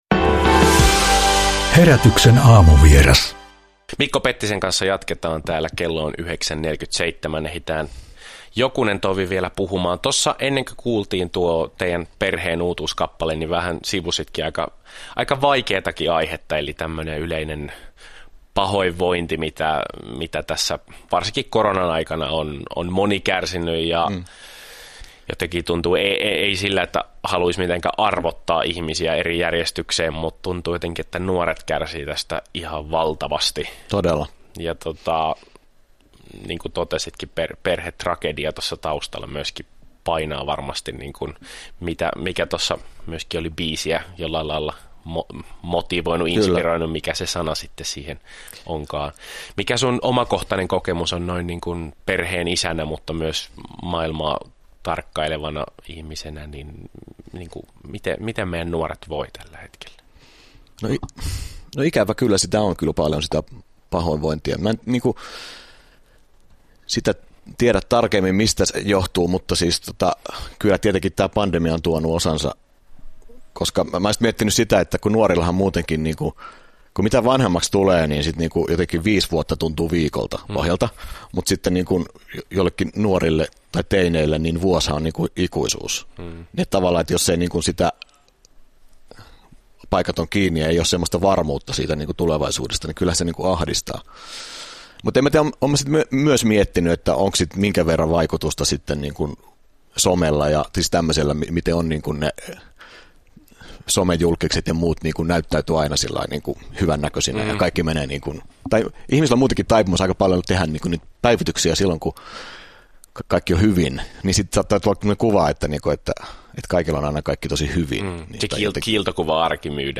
Herätys! -aamulähetyksessä tallenteena